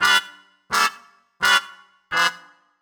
Index of /musicradar/gangster-sting-samples/85bpm Loops
GS_MuteHorn_85-G.wav